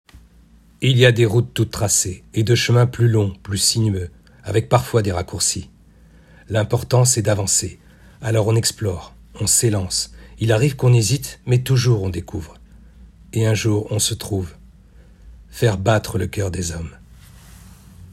Voix off
- Ténor